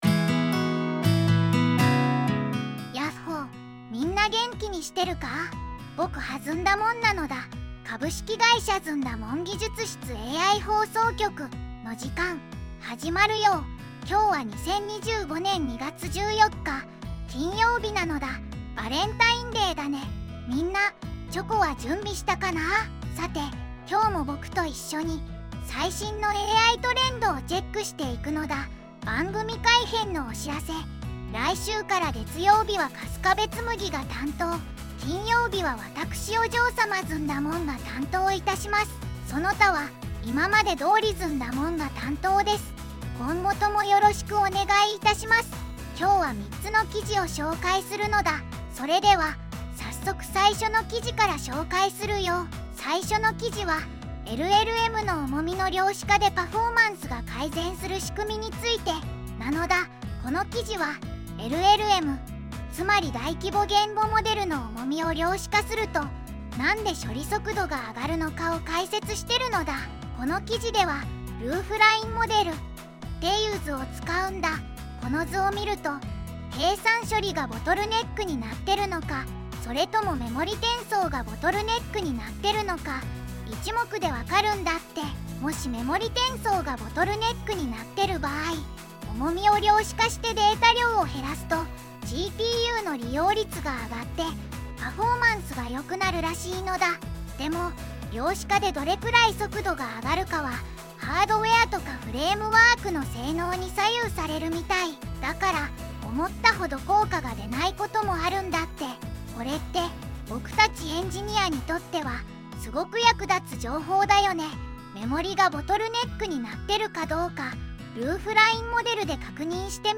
ずんだもん